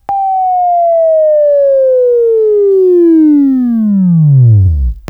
Buzz